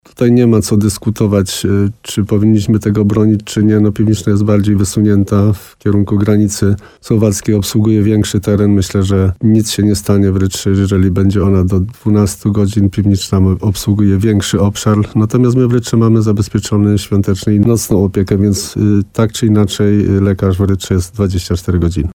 Jan Kotarba mówił w programie Słowo za Słowo w radiu RDN Nowy Sącz, że decyzja Sądeckiego Pogotowia Ratunkowego jest dla niego zrozumiała.